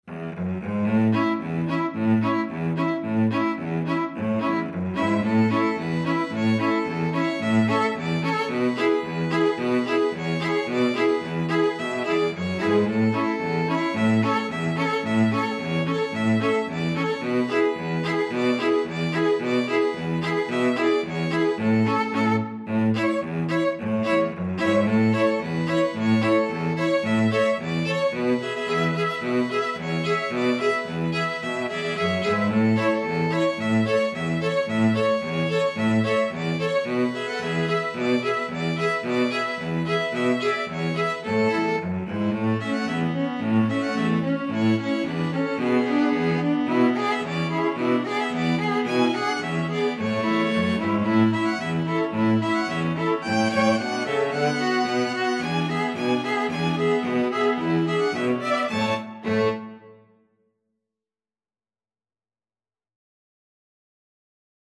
Free Sheet music for String Quartet
Violin 1Violin 2ViolaCello
Traditional Music of unknown author.
4/4 (View more 4/4 Music)
A major (Sounding Pitch) (View more A major Music for String Quartet )
Playfully =c.110
Classical (View more Classical String Quartet Music)